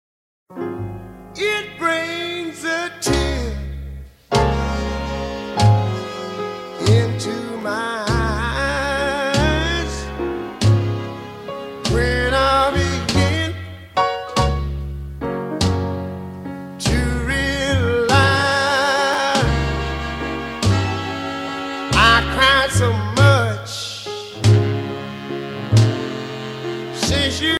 danse : slow
Pièce musicale éditée